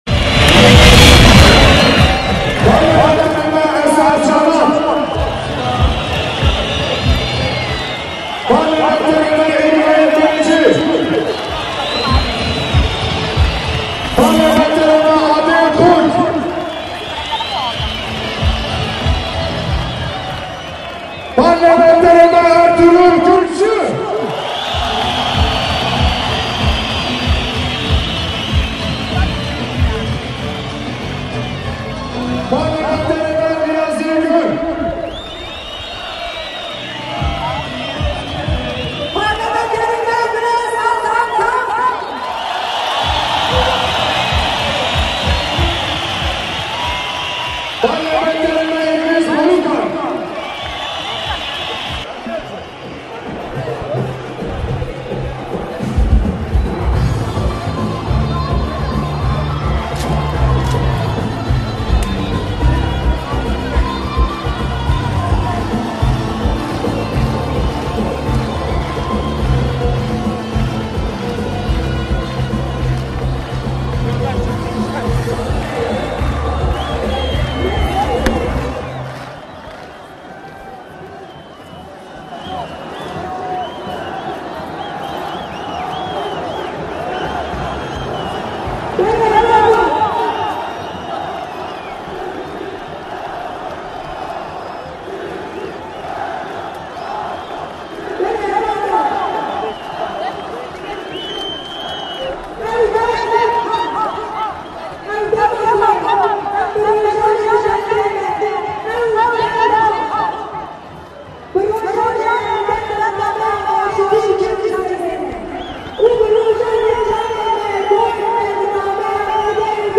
Le musiche del Newroz
MusicaNewrozDiyarbakir.mp3